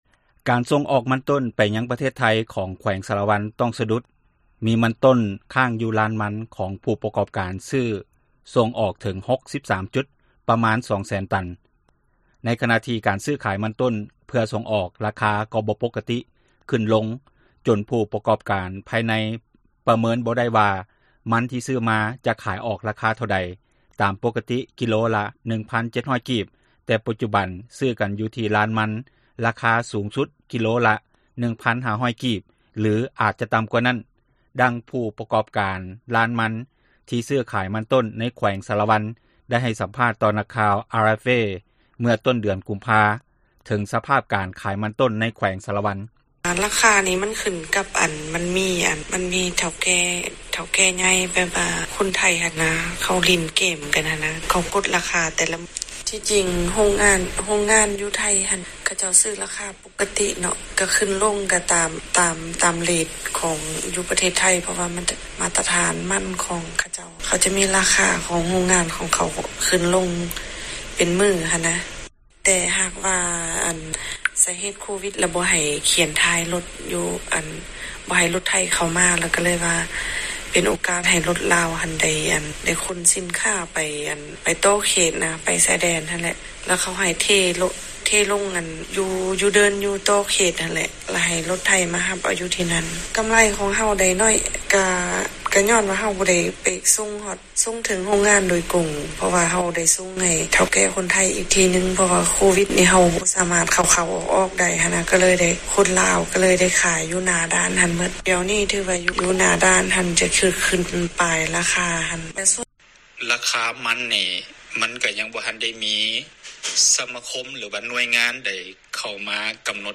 ການສັມພາດ: